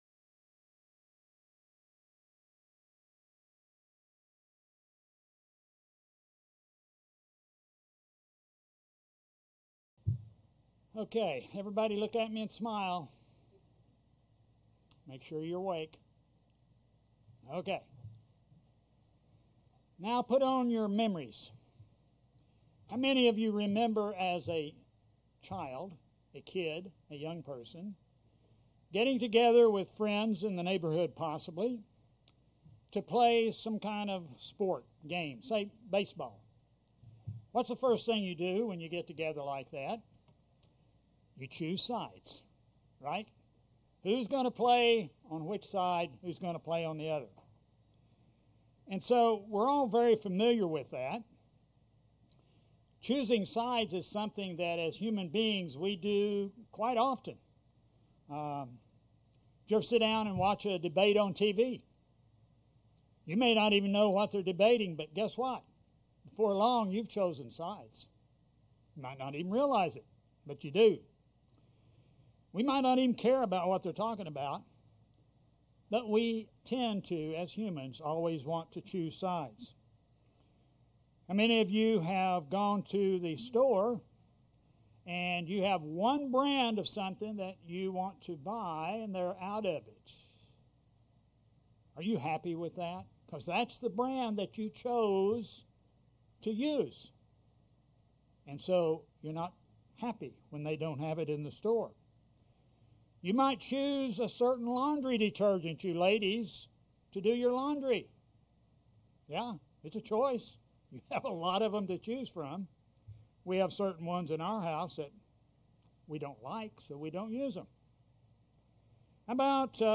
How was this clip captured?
Given in Springfield, MO